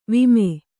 ♪ vime